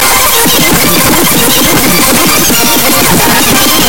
تسميات : tone loud very electric
نغمة رنين رقمي صاخب